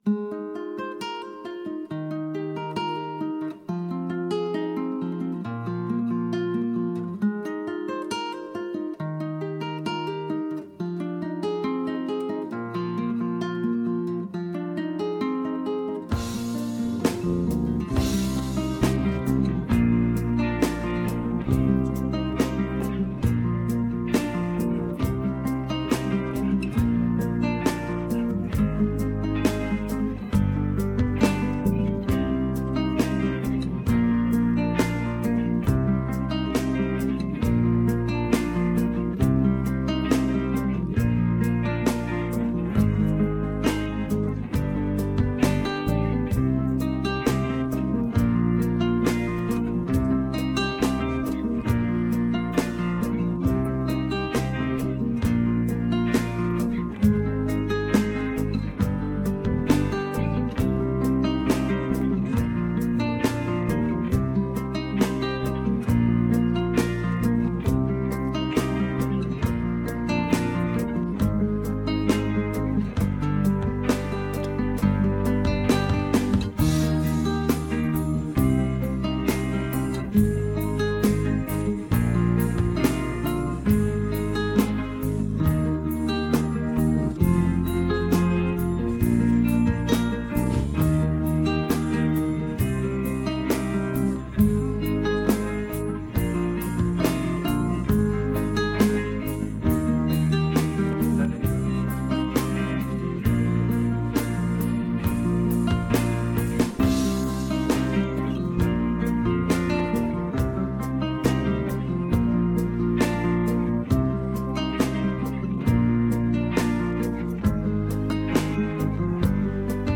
Übungsaufnahmen - Lüttje witte Ballen
Runterladen (Mit rechter Maustaste anklicken, Menübefehl auswählen)   Lüttje witte Ballen (Playback)
Luettje_witte_Ballen__4_Playback.mp3